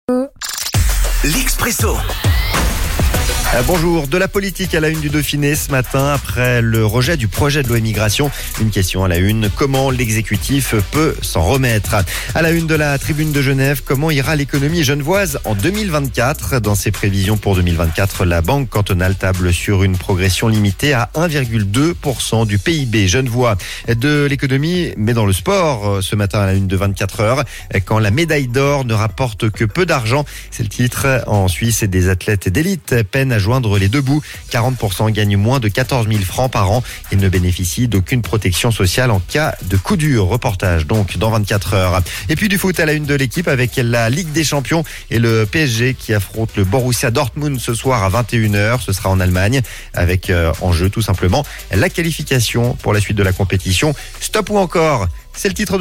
La revue de presse